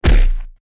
face_hit_Large_78.wav